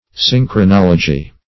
Meaning of synchronology. synchronology synonyms, pronunciation, spelling and more from Free Dictionary.
synchronology.mp3